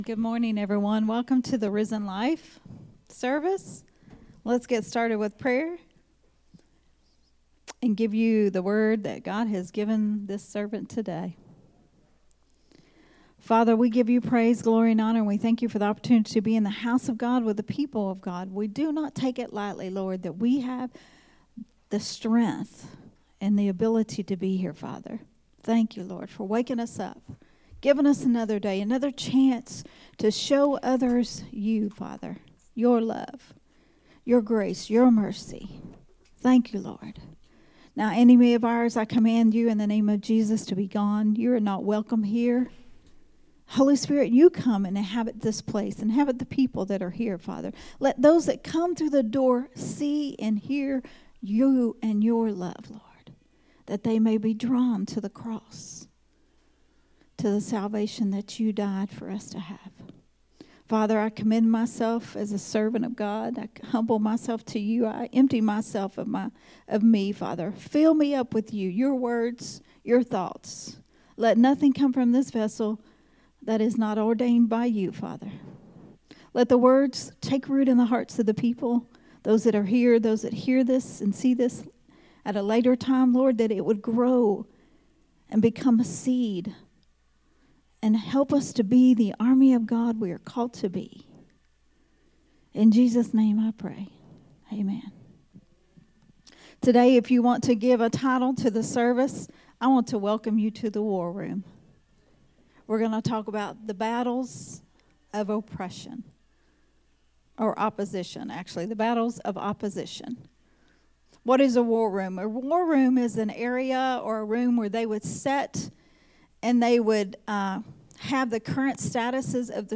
a Sunday Morning Risen Life teaching
recorded at Unity Worship Center on Sunday, November 17th, 2024.